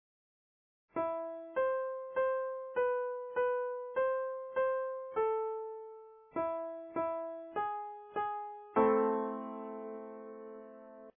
A nice example of the leading tone in a minor melody is "Go Down Moses", the 19th century gospel standard. Here in 'A' minor and starting out with a nice leap of a minor 6th, the line clearly sounds the leading tone / half step resolution at its close.